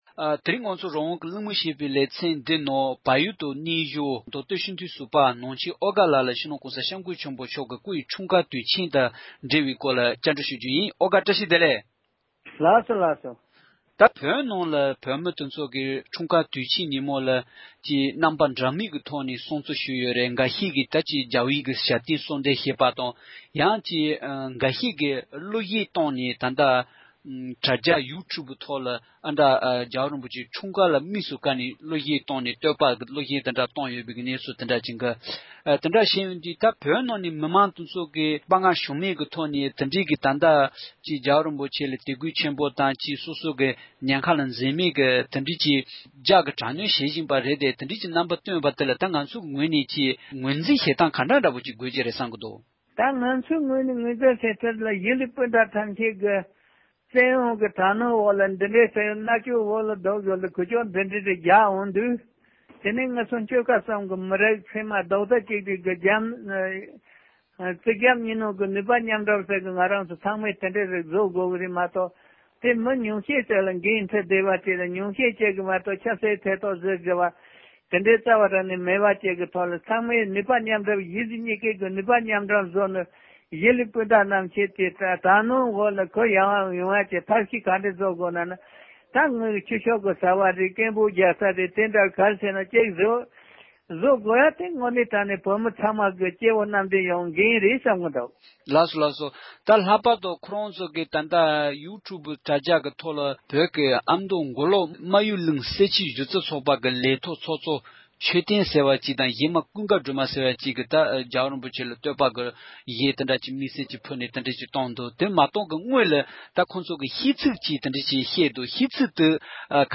༸གོང་ས་མཆོག་གི་སྐུའི་འཁྲུངས་སྐར་ཐད་གླེང་མོལ།